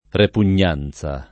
repugnanza [ repun’n’ # n Z a ]